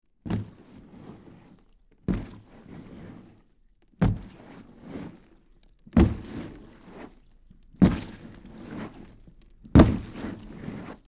Alien Walking; Large Dull Thud, Wet Footsteps And Movement.